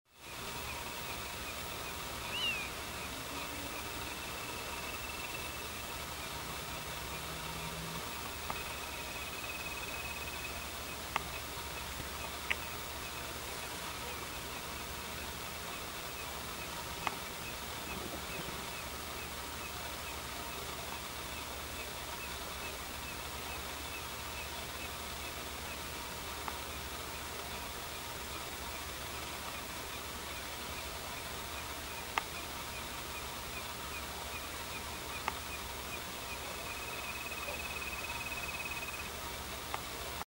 Inambú Pálido (Nothura darwinii)
Fase de la vida: Adulto
Localización detallada: Camino cercano a la Laguna de Guatraché.
Condición: Silvestre
Certeza: Vocalización Grabada